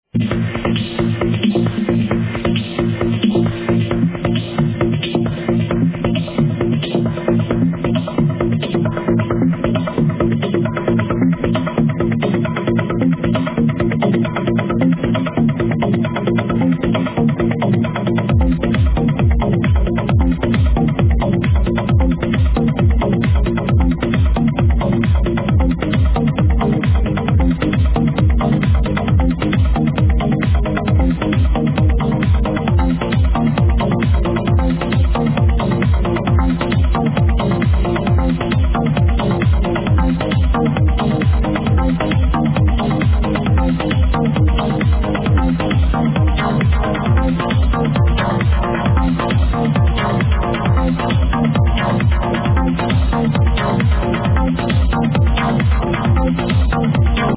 Killa Prog tune, please ID!